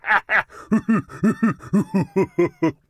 Sounds / Enemys / Giant / G2_laugh1.ogg
G2_laugh1.ogg